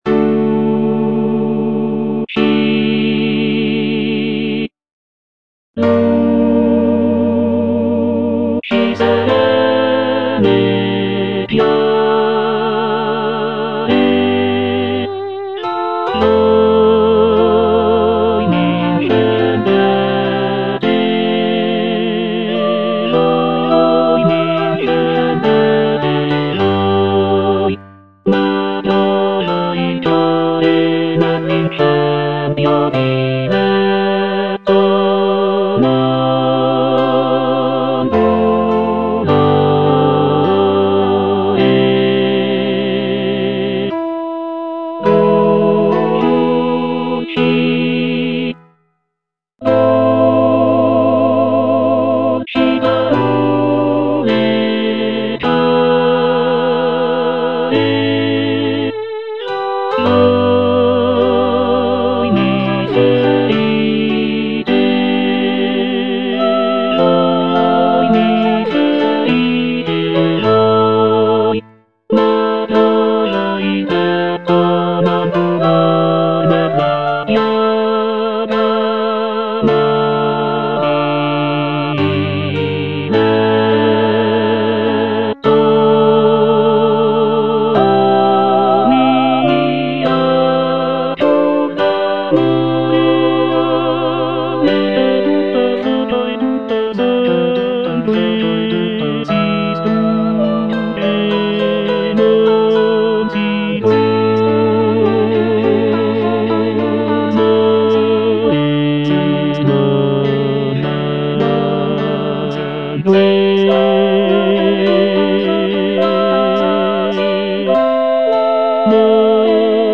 C. MONTEVERDI - LUCI SERENE E CHIARE Tenor (Emphasised voice and other voices) Ads stop: auto-stop
"Luci serene e chiare" is a madrigal composed by Claudio Monteverdi, one of the most important figures in the development of Baroque music.
The madrigal is known for its intricate vocal lines and rich textures, creating a sense of serenity and clarity in the music.